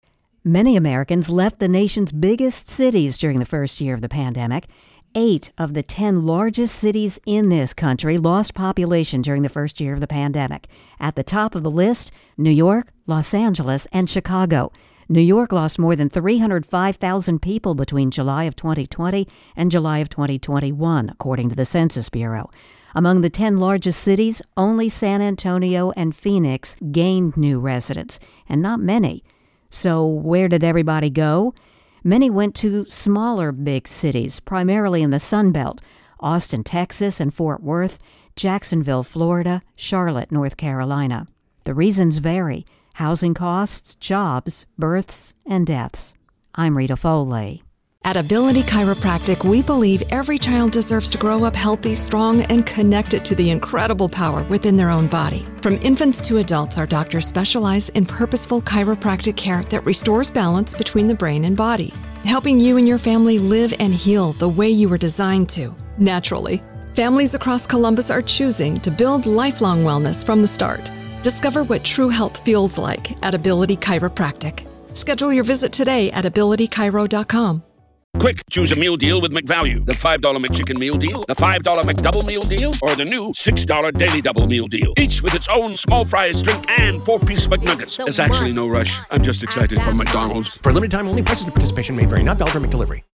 Intro+Voicer